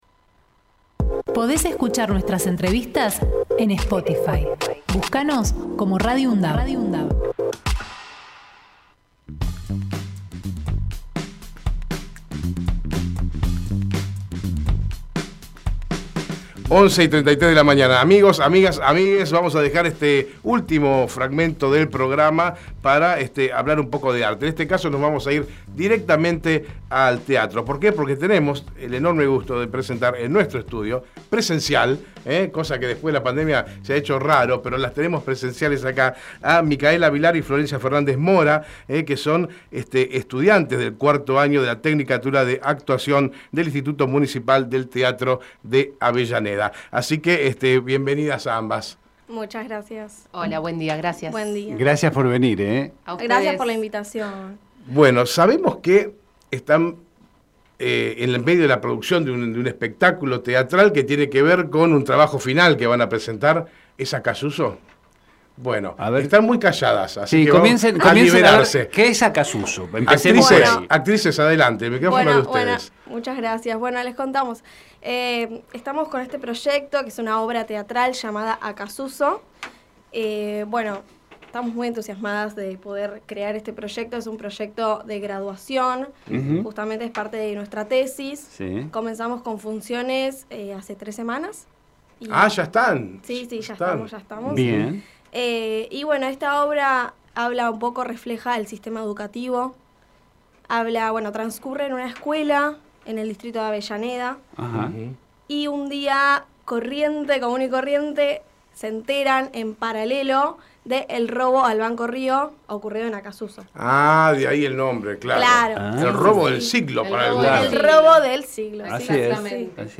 Compartimos la entrevista realizada en Hacemos PyE